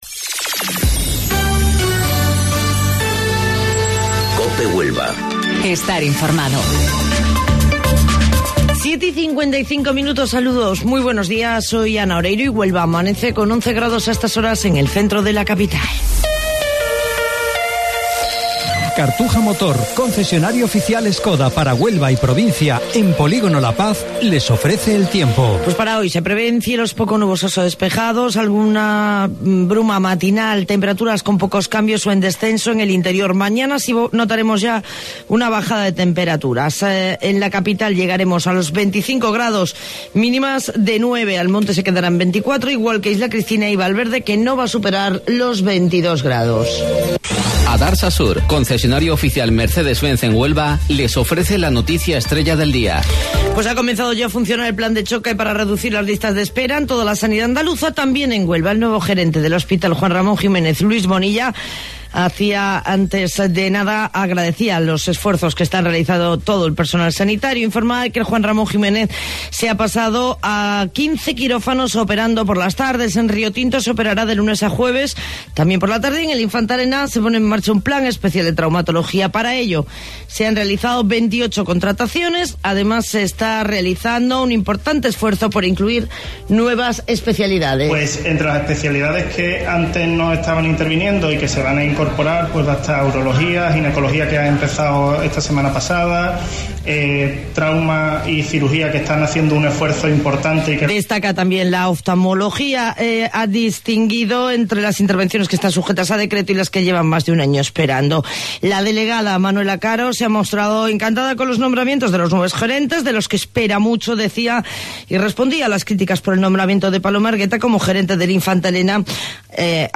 AUDIO: Informativo Local 07:55 del 3 de Abril